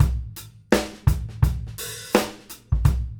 GROOVE 220LL.wav